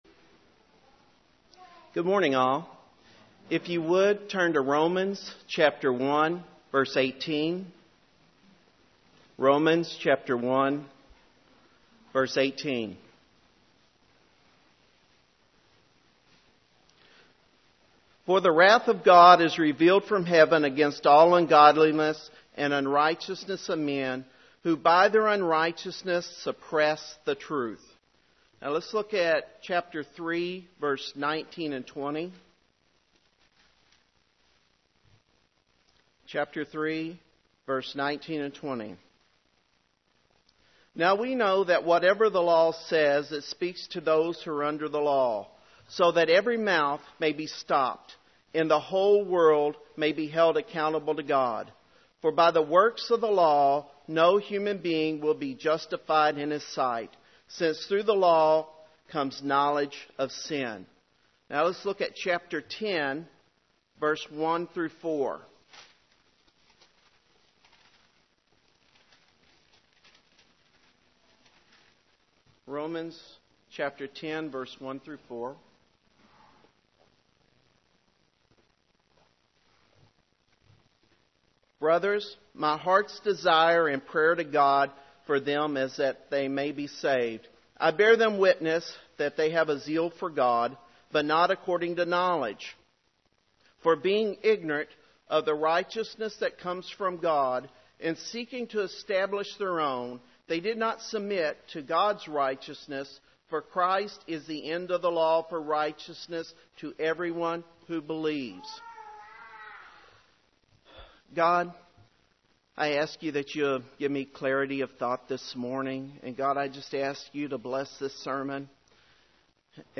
Romans 10:1-4 Service Type: Sunday Morning Three reasons why people do not feel a need for the gospel.